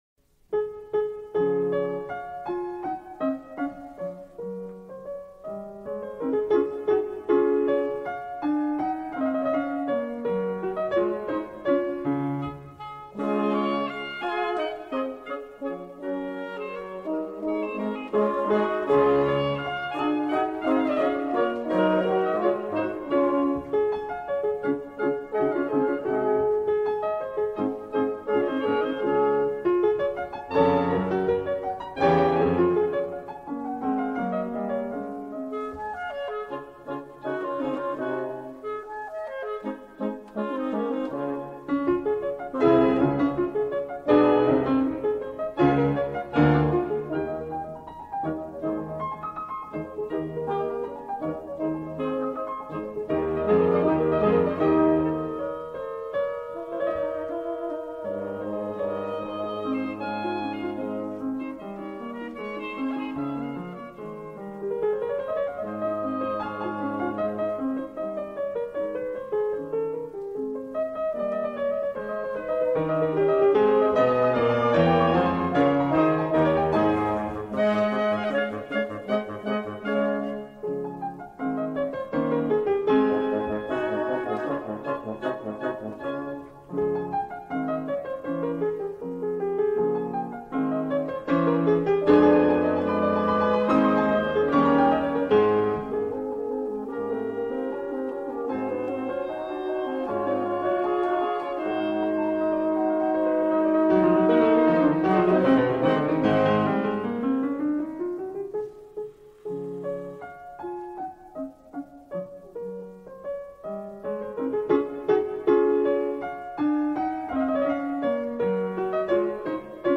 Greenwich Paisible : Pluie Village Calme